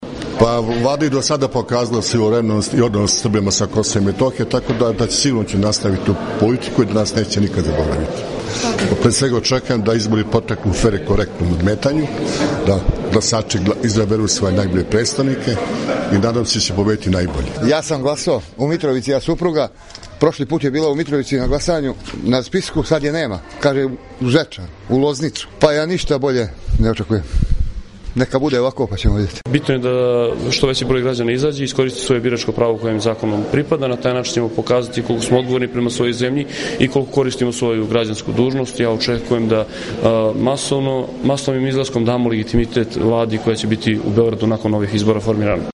Anketa: Glasači Severne Mitrovice